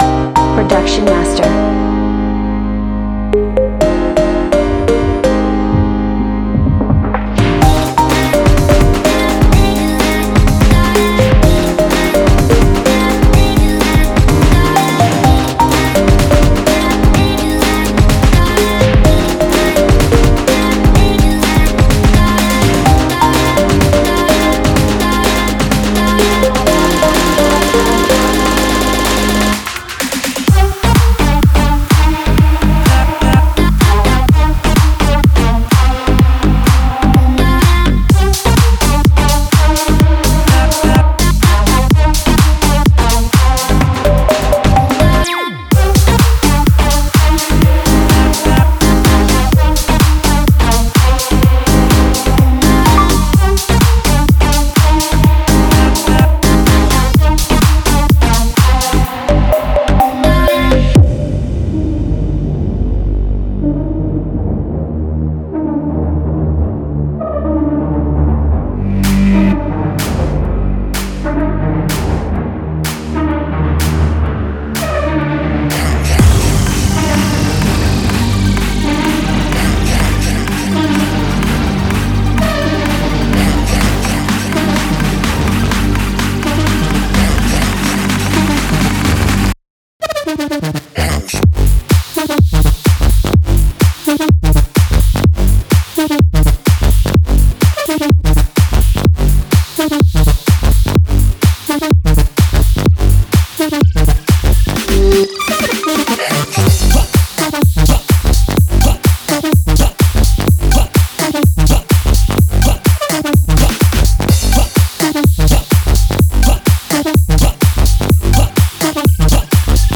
4. Future House
这些鼓充满了沉重的敲打声，酥脆的掌声和经典的帽子，将为您未来的房屋生产增加令人难以置信的能量！